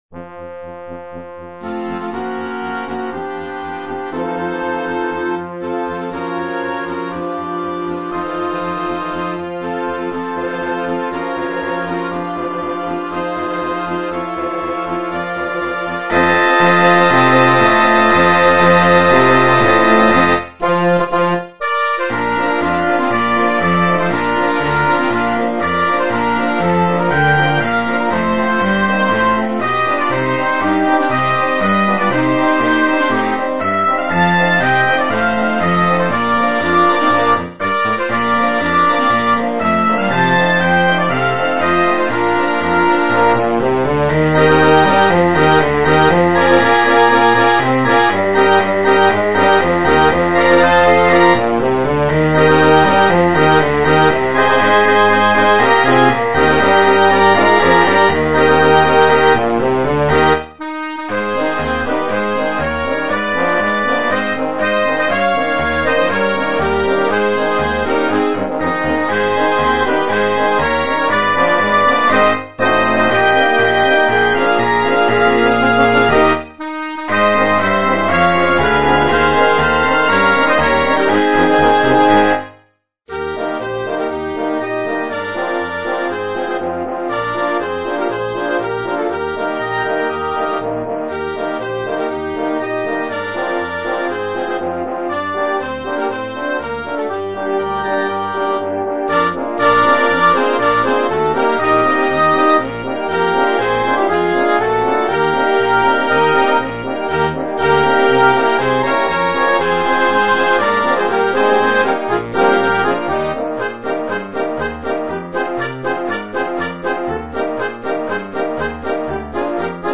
Per banda